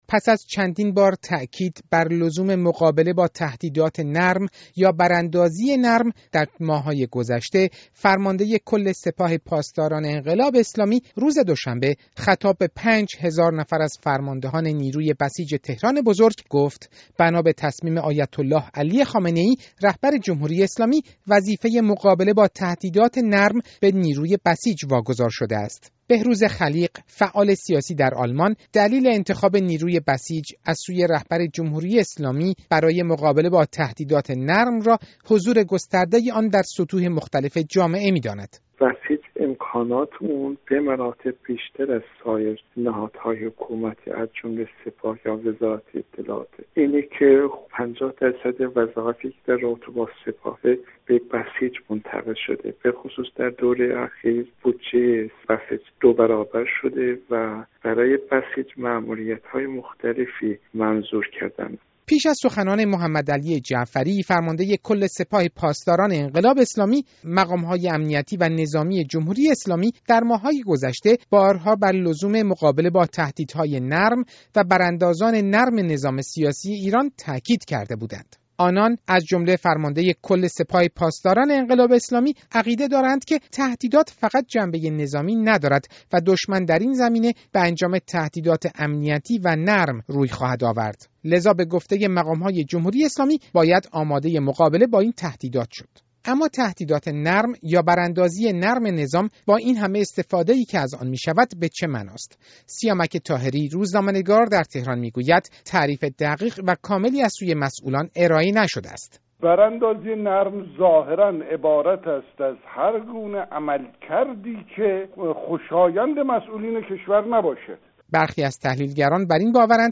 گزارش
گفت و گو با سه فعال سیاسی و روزنامه نگار